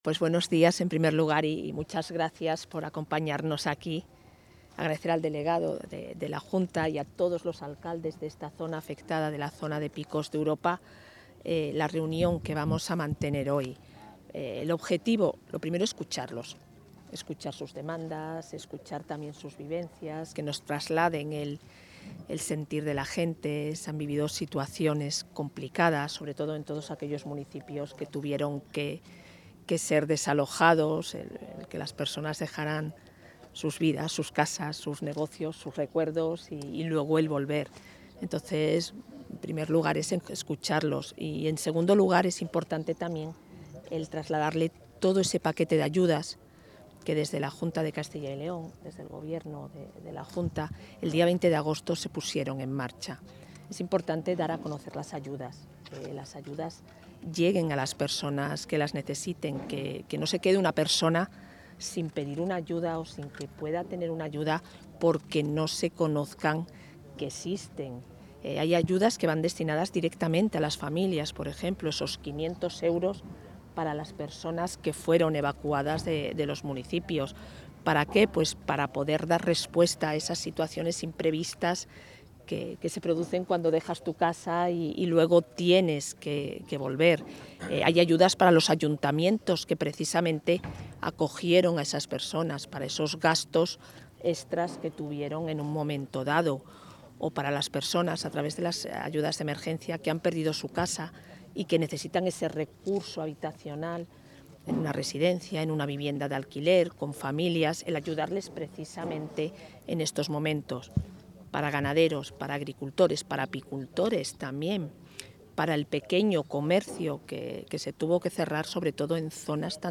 Declaraciones de la vicepresidenta de la Junta.